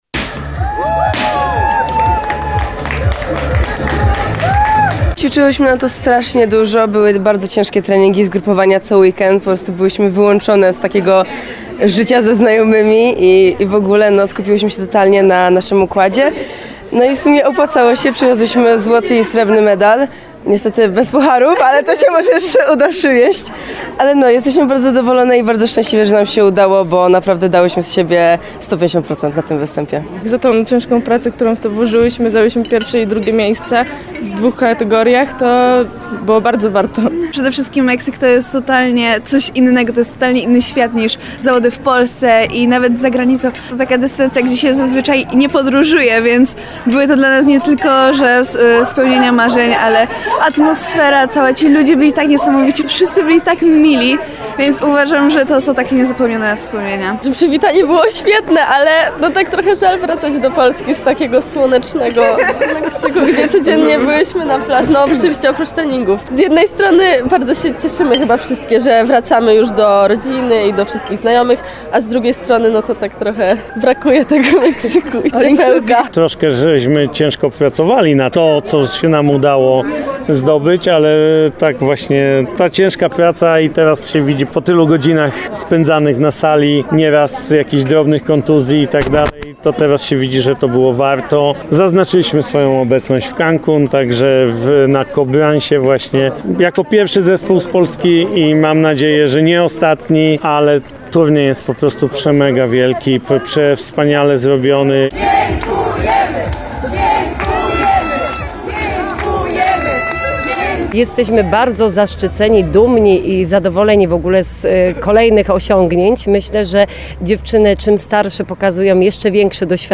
Chociaż puchary zostały zatrzymane chwilowo na lotnisku, to przed MOSIR-em w Nowym Sączu, gdzie uroczyście witane były mistrzynie można było oglądać ich medale.
Gorące oklaski i uściski, kwiaty, konfetti i okrzyki na cześć zawodniczek były zasługą rodziców i członków ich rodzin, którzy przyjechali uczcić tu sukces zespołu.
powitanie-cheerleaderek.mp3